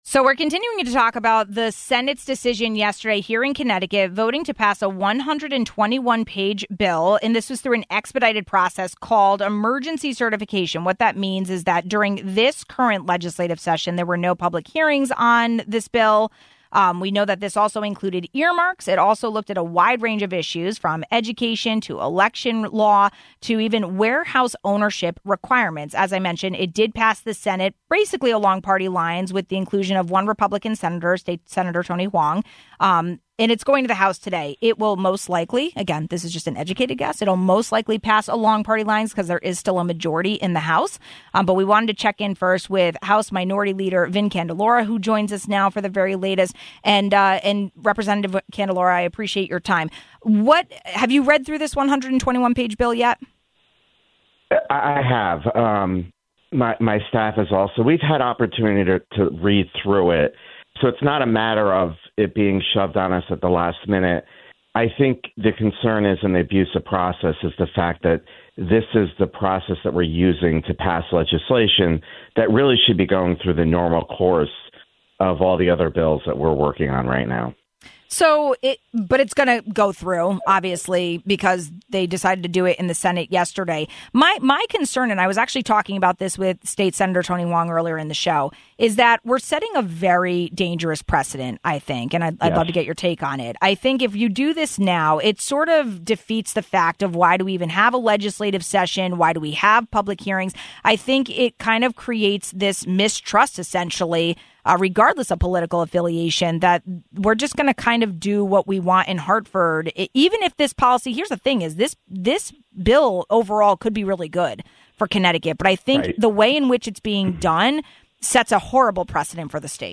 But what is the ‘emergency’ prompting the passage of a 121-page bill in the state Senate and does it point to a bad trend coming in Hartford? We spoke with House Minority Leader, Vin Candelora, about the process of e-cert legislation.